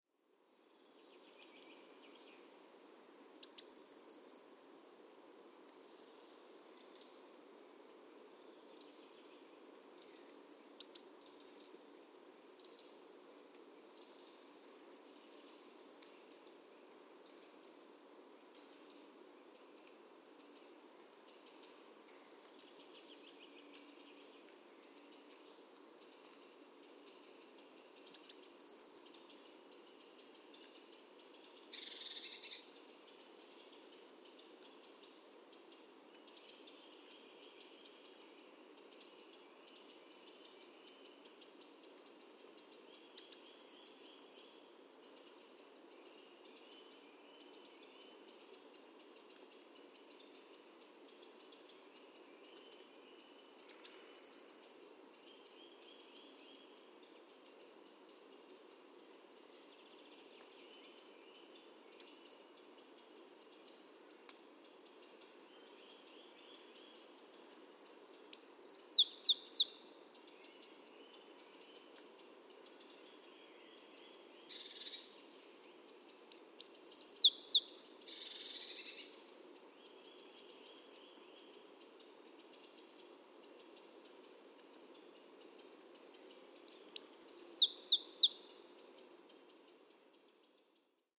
PFR10669, 1-11, 150324, Eurasian Blue Tit Cyanus caeruleus, song
north-western Saxony, Germany, Telinga parabolic reflector